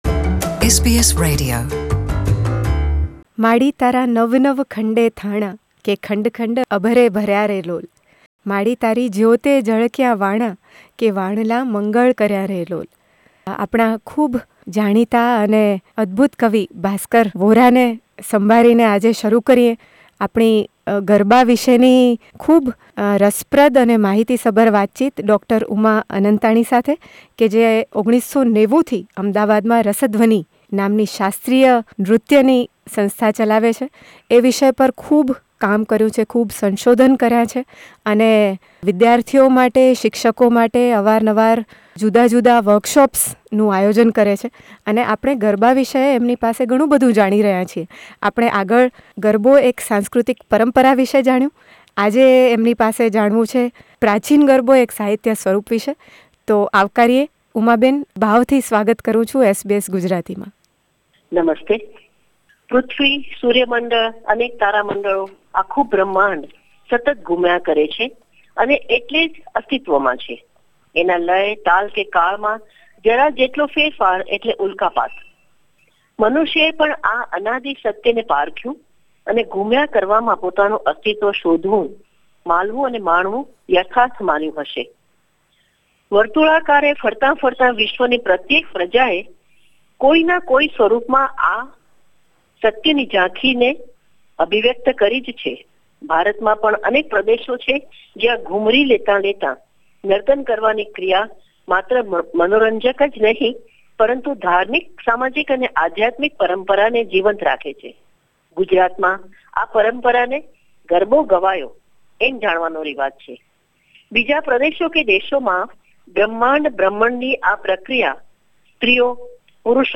એમની સાથેની ચાર ભાગની વાતચીતના આ બીજા ભાગમાં તેઓ વાત કરે છે પ્રાચીન ગરબાનાં સાહિત્ય અને સ્વરૂપ વિષે.